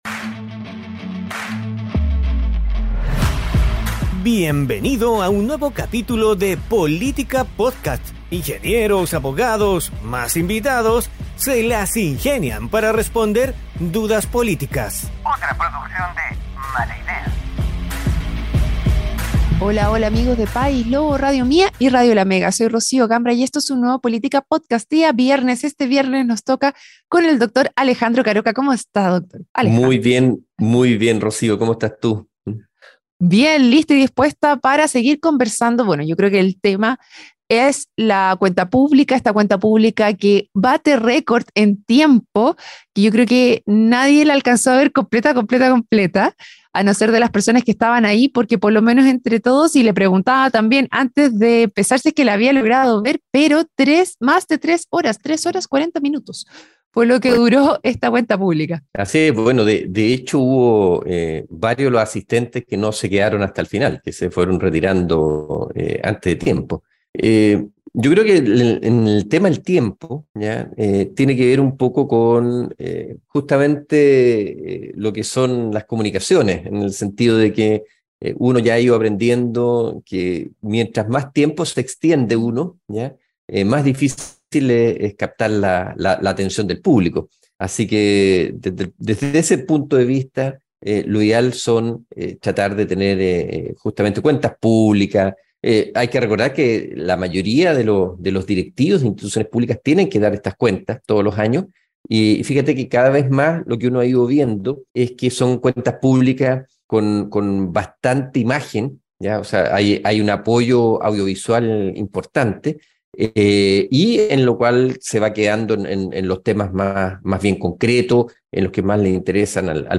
conduce "Política Podcast" programa donde junto a panelistas estables e invitados tratan de responder dudas políticas.